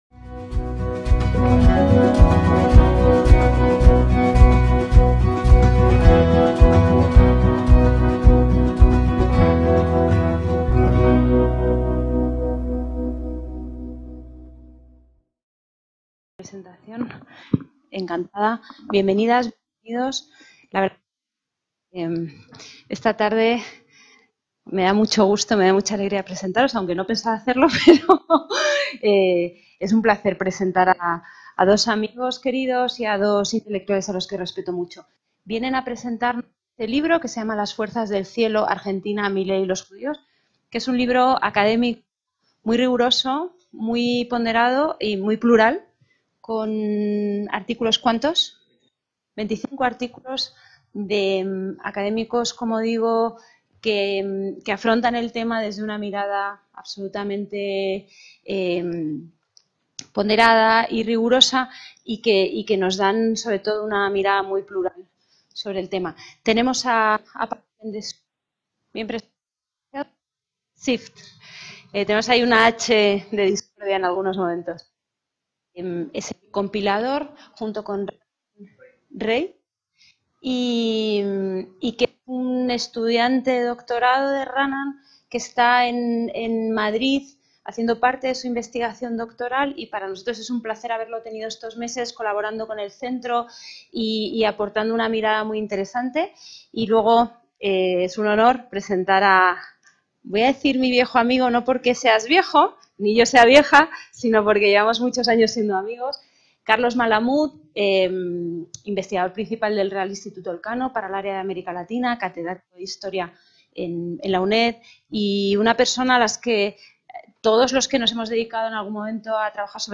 (Centro Sefarad Israel, Madrid, 31/3/2025)
ACTOS EN DIRECTO